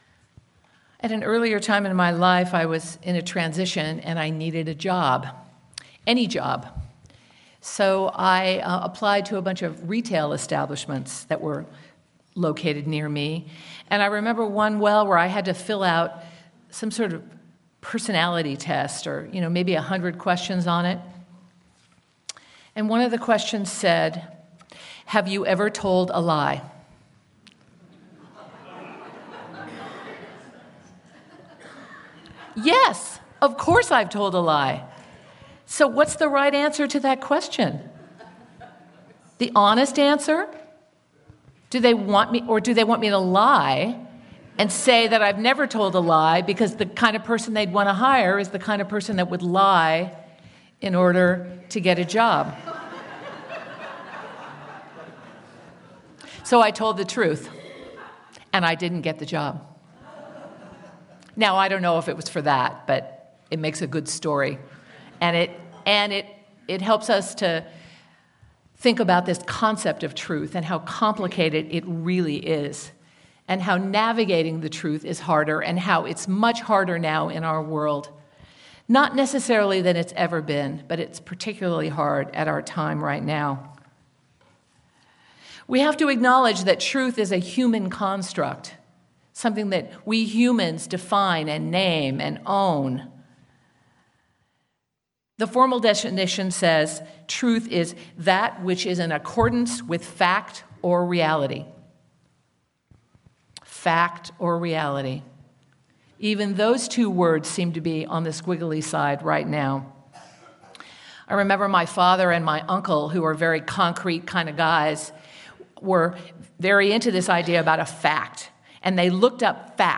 Sermon-Truth-Lies-and-Integrity.mp3